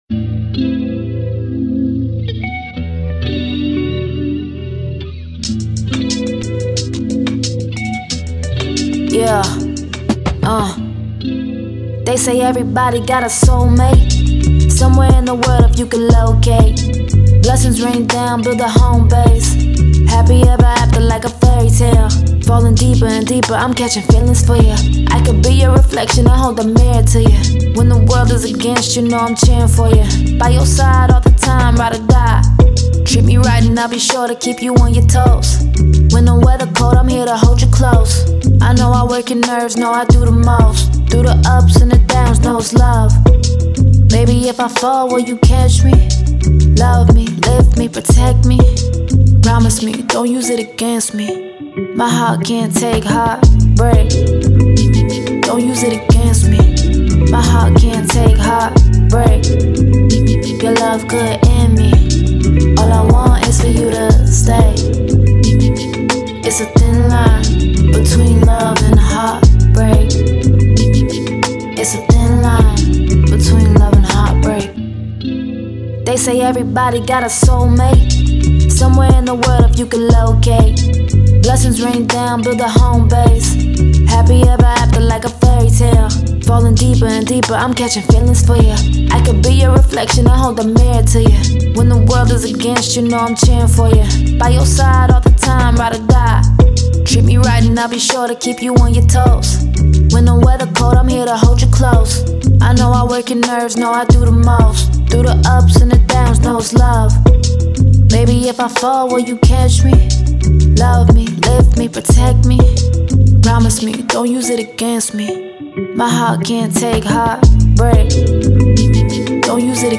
Hip Hop
E Minor